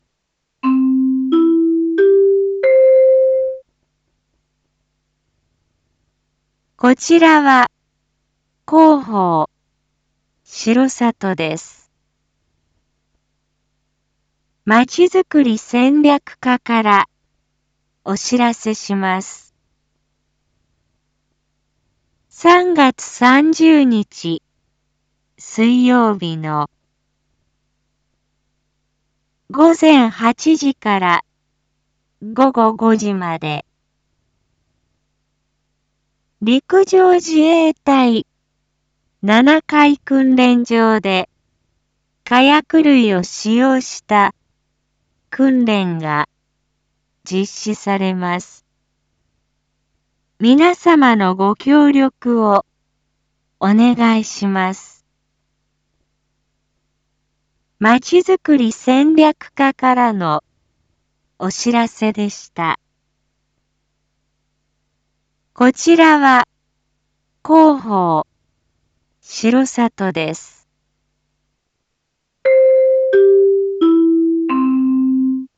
一般放送情報
Back Home 一般放送情報 音声放送 再生 一般放送情報 登録日時：2022-03-29 19:01:17 タイトル：R4.3.29 19時 放送分 インフォメーション：こちらは広報しろさとです。